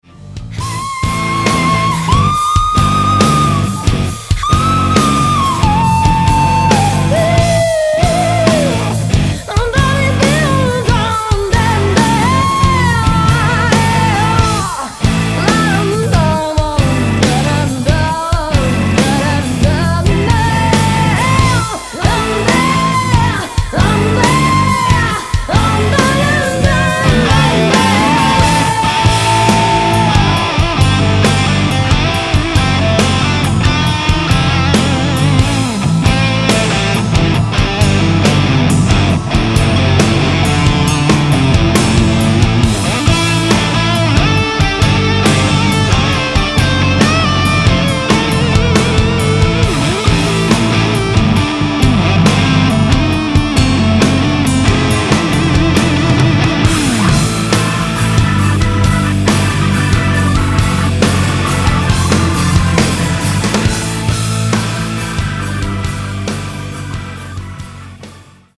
Nahrávací studio v Lipově audio / digital
Trocha folku a rocku nezaškodí.